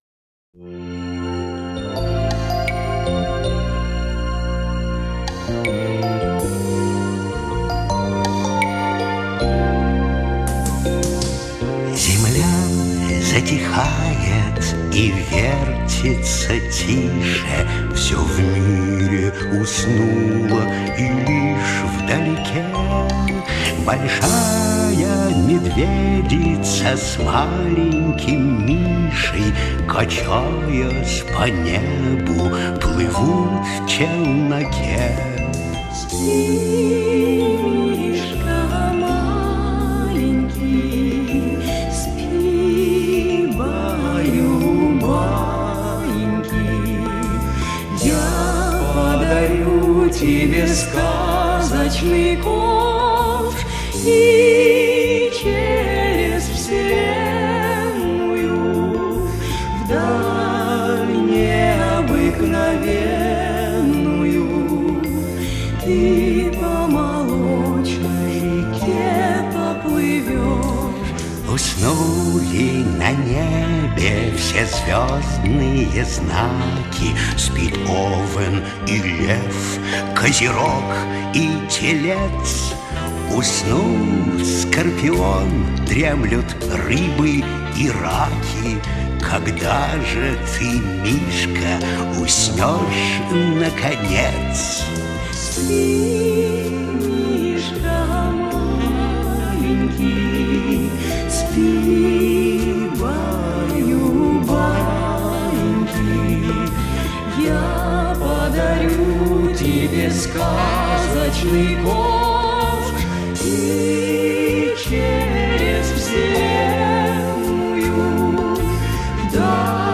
• Категория: Детские песни
колыбельная